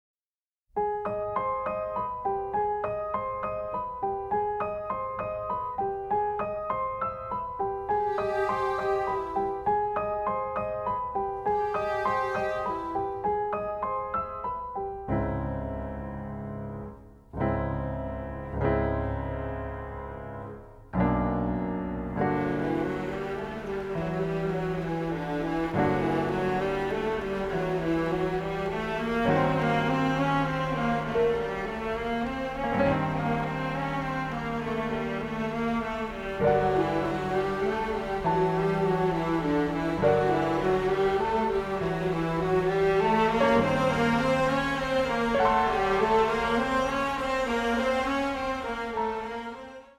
radiant melodramatic score